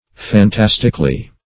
fantasticly - definition of fantasticly - synonyms, pronunciation, spelling from Free Dictionary Search Result for " fantasticly" : The Collaborative International Dictionary of English v.0.48: Fantasticly \Fan*tas"tic*ly\, adv.
fantasticly.mp3